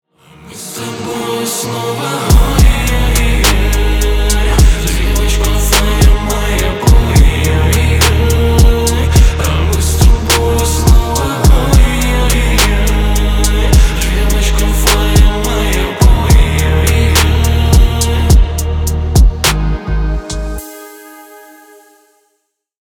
Поп Музыка
тихие # спокойные